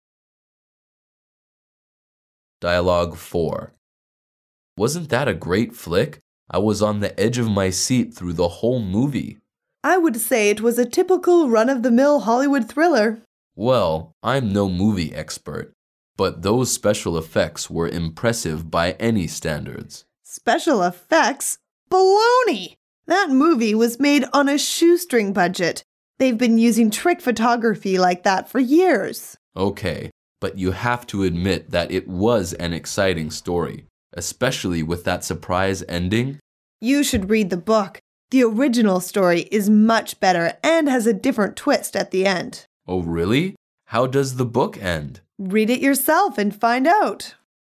Dialoug 4